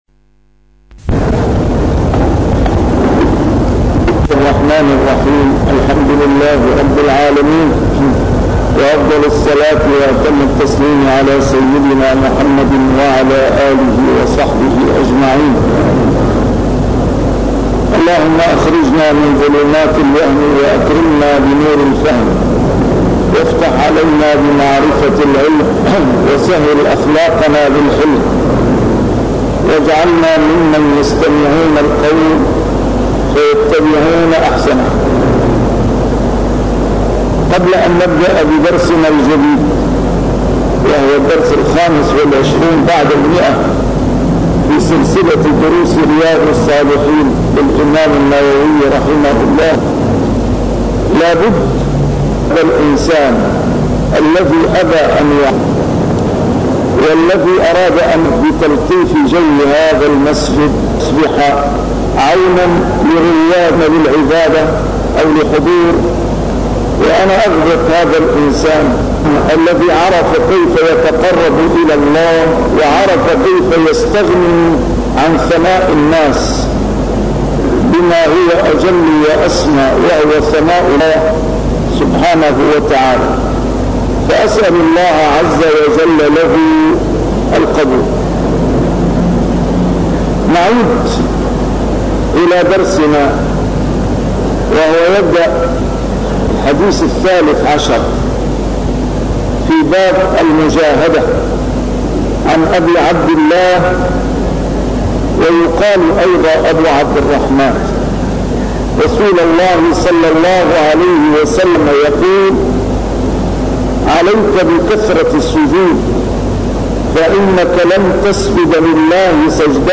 A MARTYR SCHOLAR: IMAM MUHAMMAD SAEED RAMADAN AL-BOUTI - الدروس العلمية - شرح كتاب رياض الصالحين - 125- شرح رياض الصالحين: المجاهدة